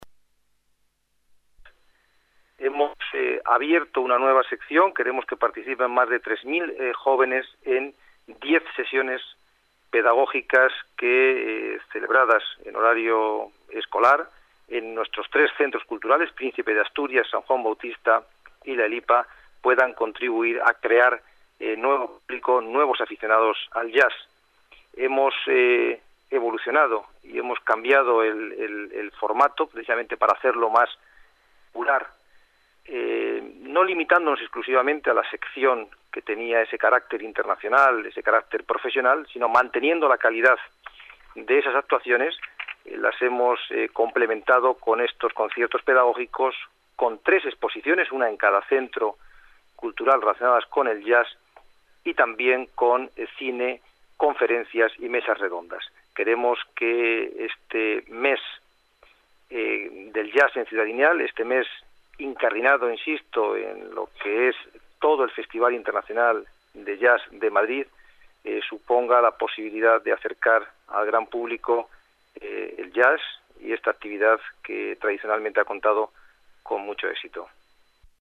Nueva ventana:Declaraciones de Troitiño